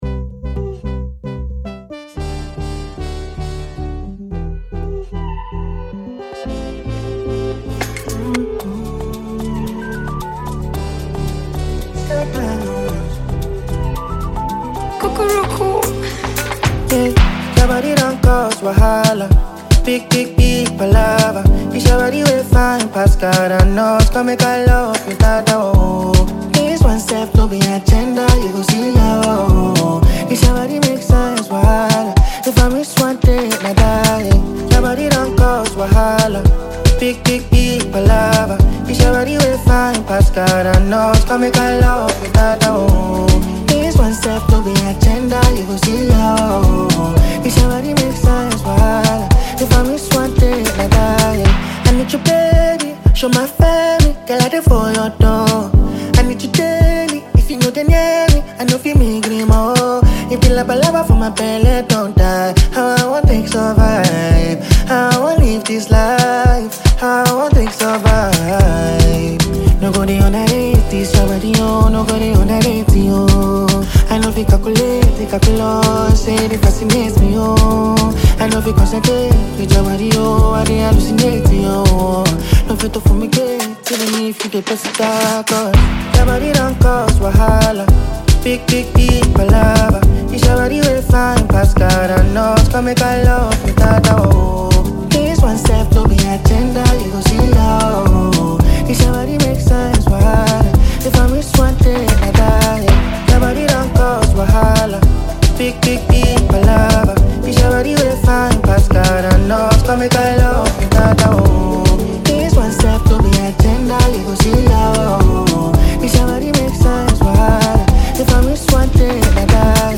a vibrant and catchy tune
Afrobeat